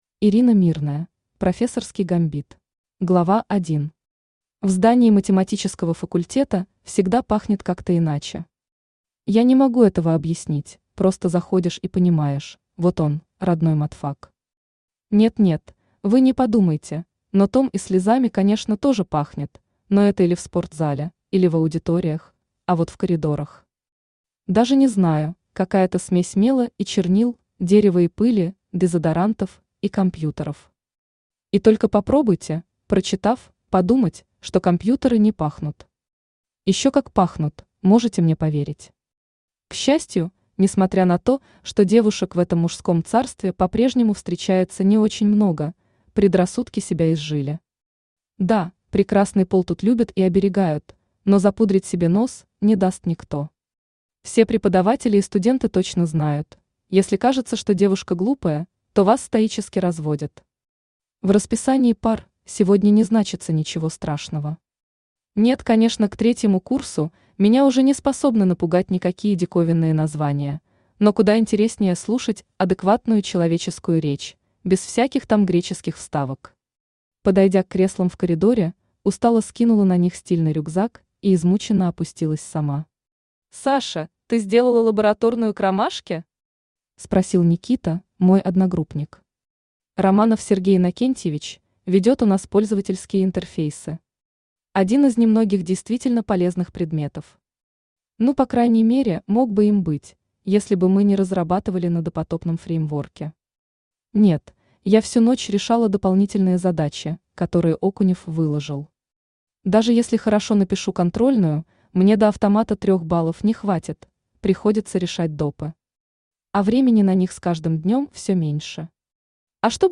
Аудиокнига Профессорский гамбит | Библиотека аудиокниг
Aудиокнига Профессорский гамбит Автор Ирина Мирная Читает аудиокнигу Авточтец ЛитРес.